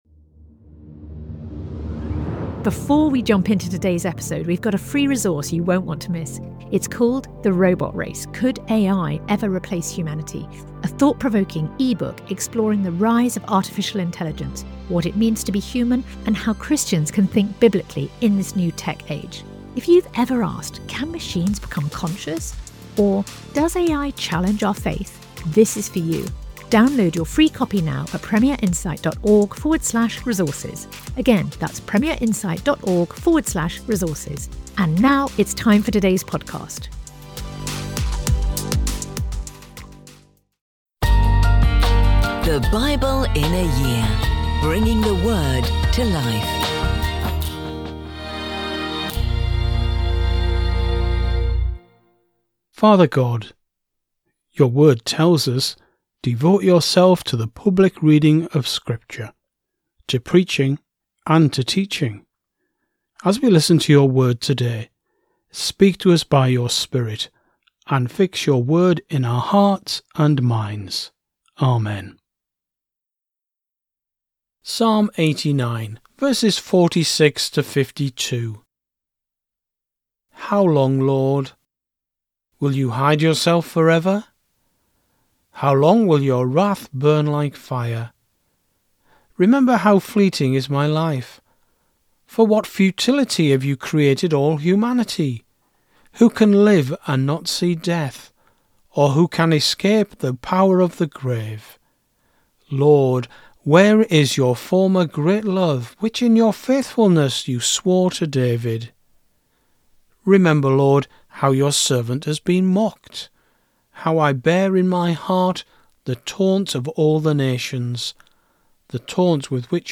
Today's readings come from Numbers 8-10; 2 Timothy 1